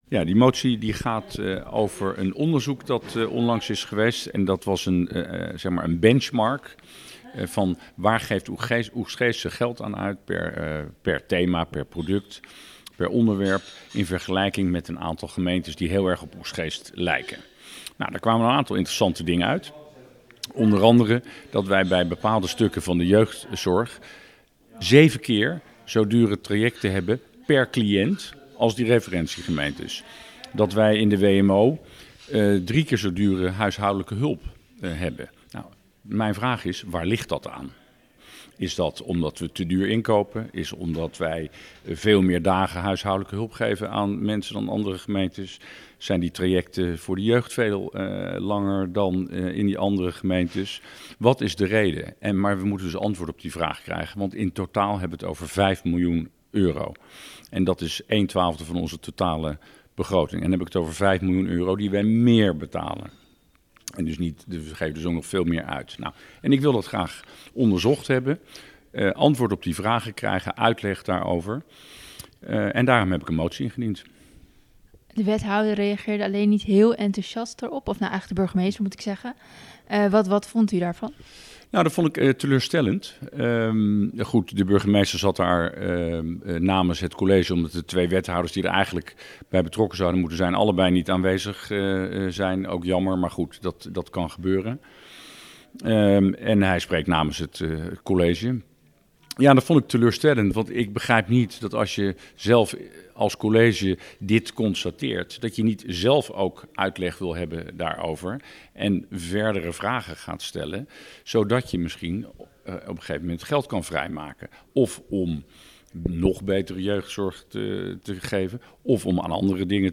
VVD-raadslid Matthijs Huizing over de kosten voor jeugdzorg en Wmo